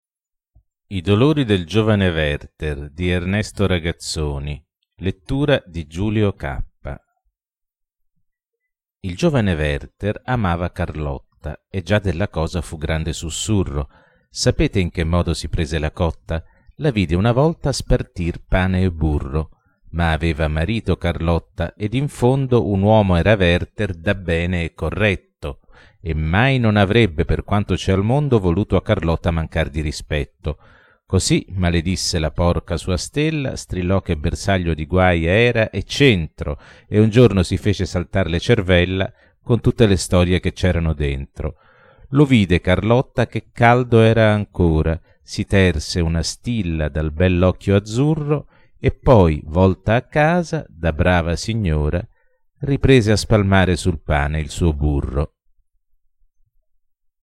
Poesie recitate da artisti